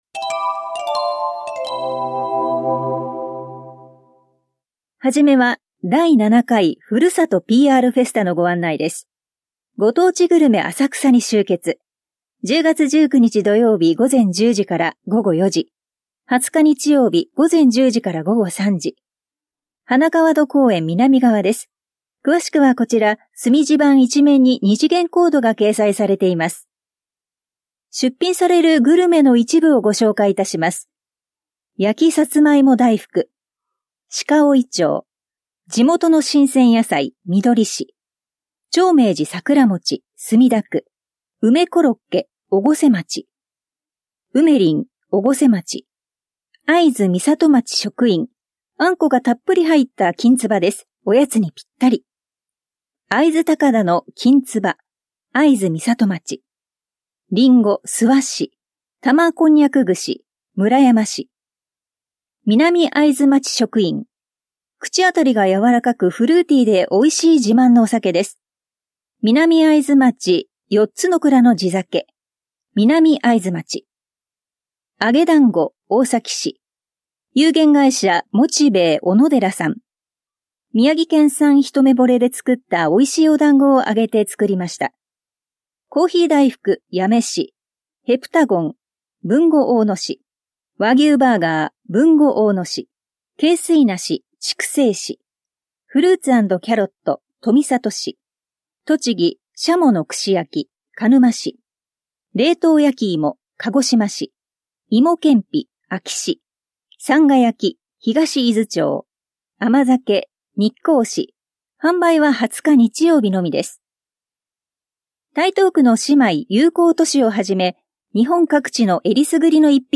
広報「たいとう」令和6年10月5日号の音声読み上げデータです。